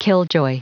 Prononciation du mot killjoy en anglais (fichier audio)
Prononciation du mot : killjoy